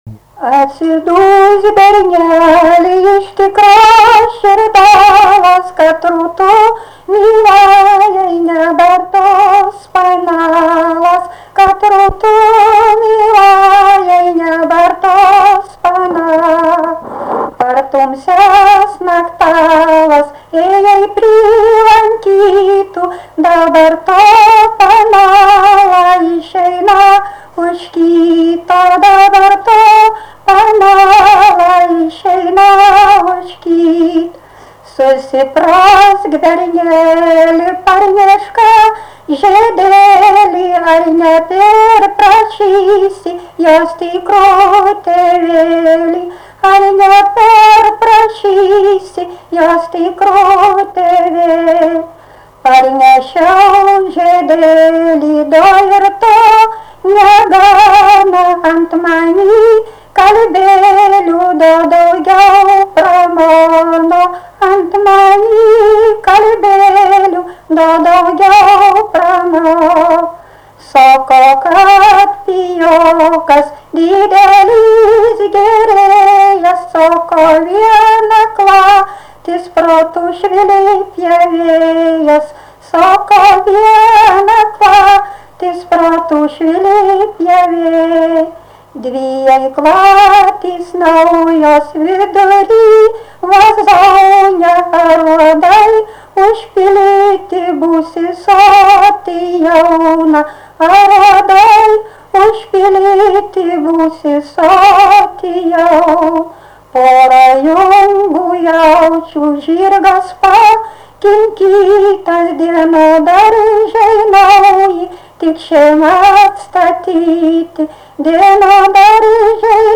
Dalykas, tema daina
Erdvinė aprėptis Suvainiai
Atlikimo pubūdis vokalinis